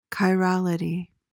PRONUNCIATION:
(ky-RAL-i-tee)